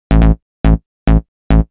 Techno / Bass / SNTHBASS091_TEKNO_140_A_SC2.wav
1 channel